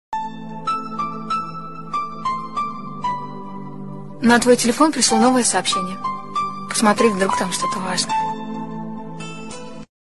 na tvoi telefon prishlo novoe soobshchenie Meme Sound Effect